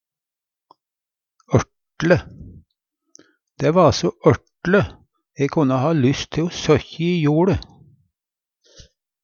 ørt'le - Numedalsmål (en-US)